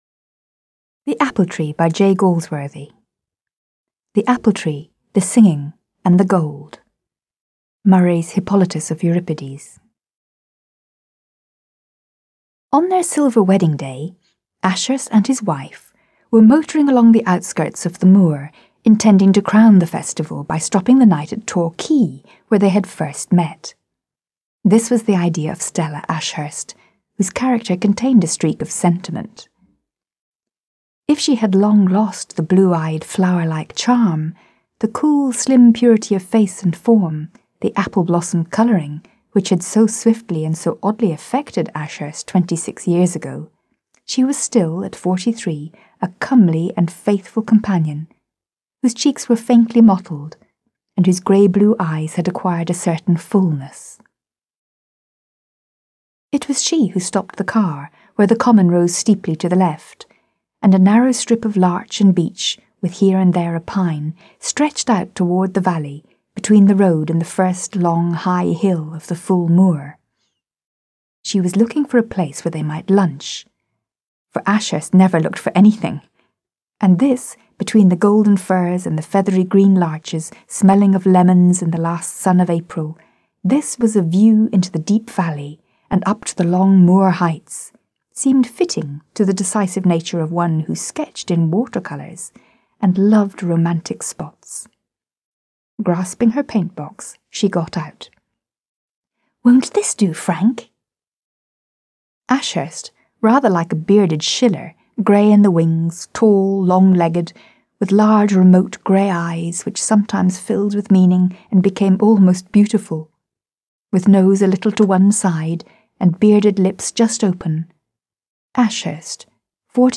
Аудиокнига Яблоня / The Apple-Tree | Библиотека аудиокниг